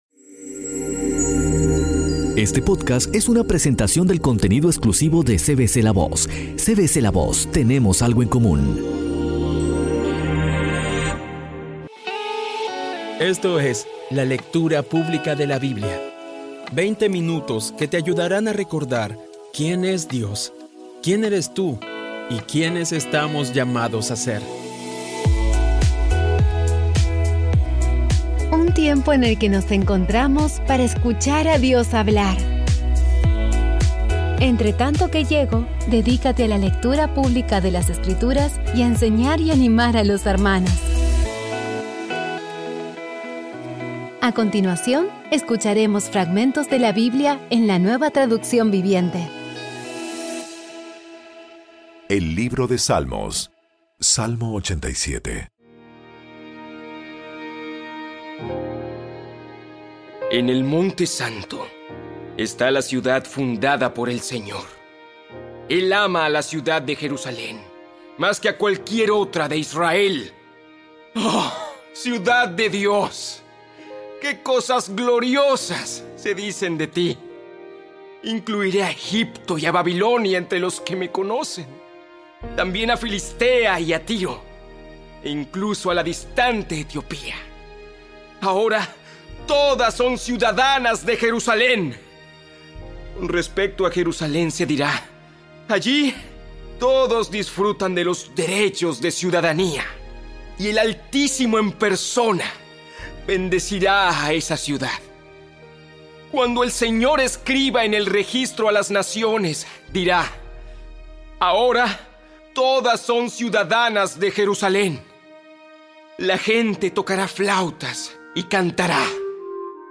Audio Biblia Dramatizada por CVCLAVOZ / Audio Biblia Dramatizada Episodio 210
Poco a poco y con las maravillosas voces actuadas de los protagonistas vas degustando las palabras de esa guía que Dios nos dio.